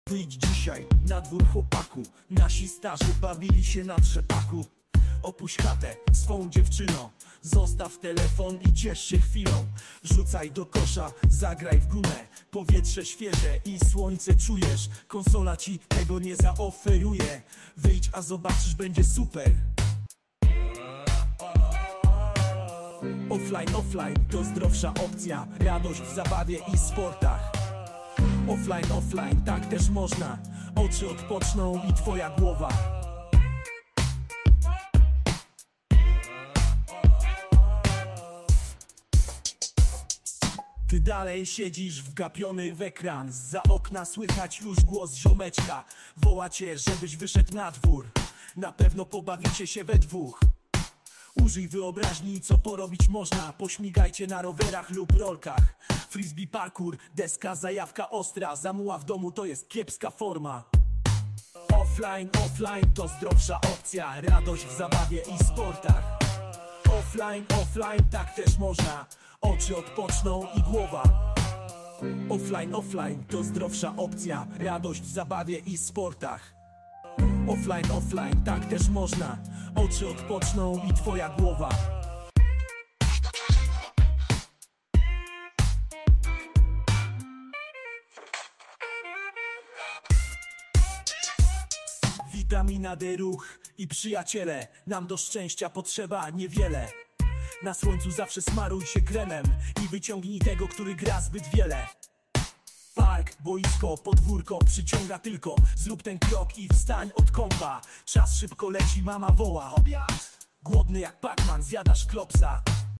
Rap w stylu 90's.